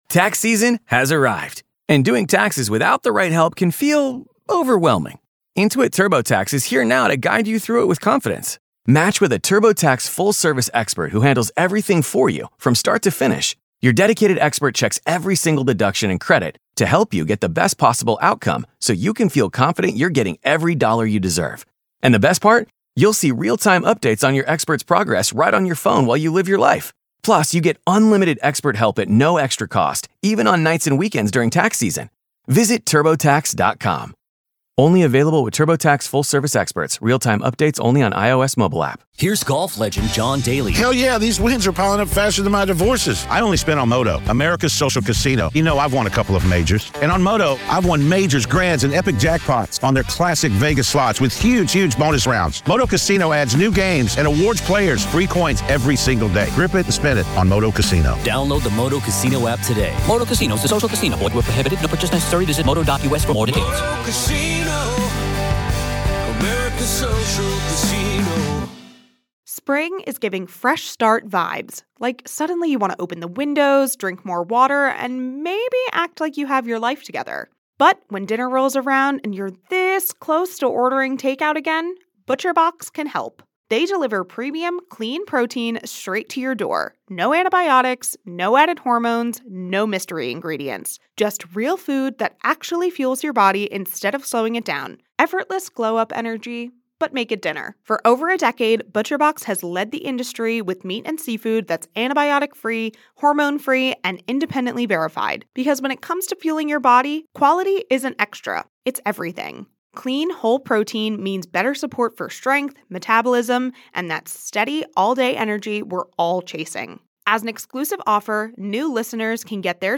This conversation dives into the unsettling space where faith, fear, and the possibility of demonic influence intersect.